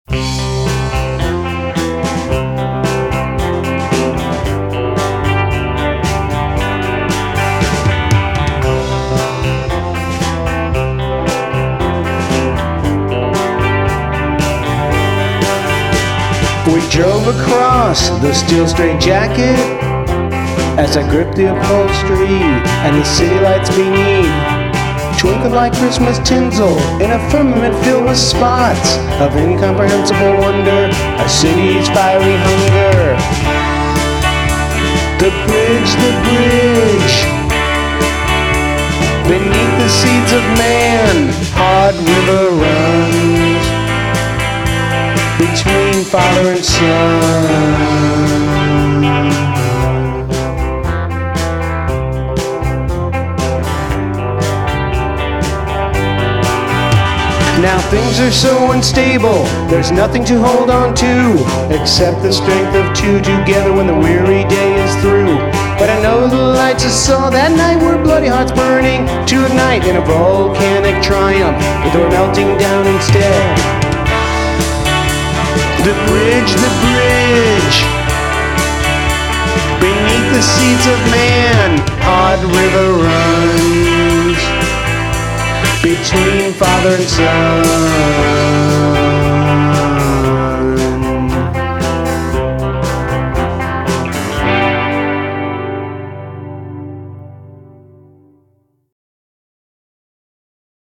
Guitars and vocals.
Drums, percussion, backup vocals.
Bass guitar.
***SUPERCHAGED 60s PSYCHEDELIC